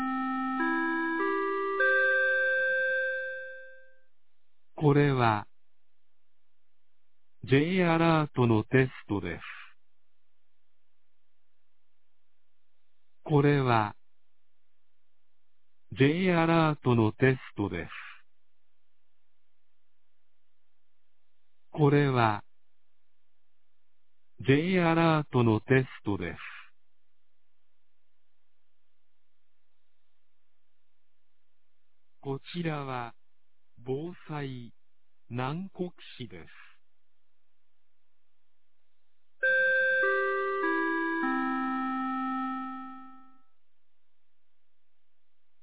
2025年11月12日 11時01分に、南国市より放送がありました。
放送音声